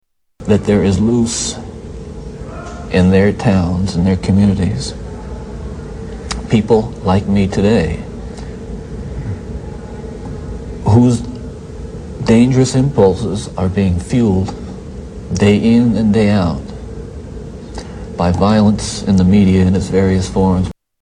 Tags: serial killer ted bundy ted bundy clips ted bundy interview ted bundy audio